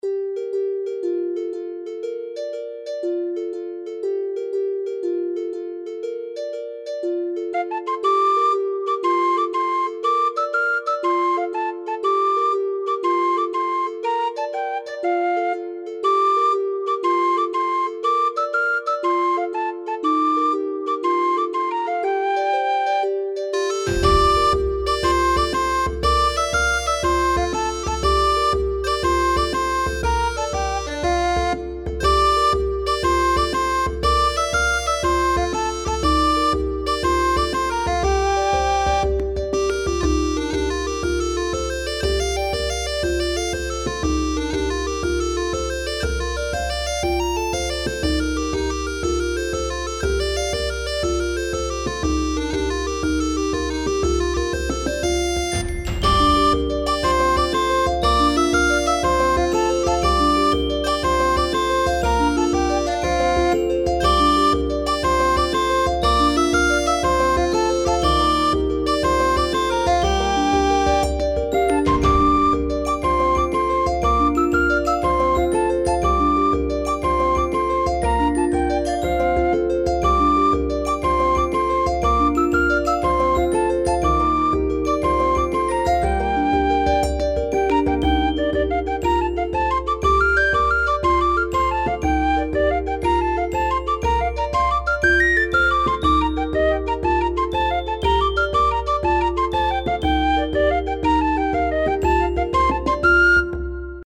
ファンタジー系フリーBGM｜ゲーム・動画・TRPGなどに！
ちょっと田舎っぽいけどみんなパワフルなんだろな。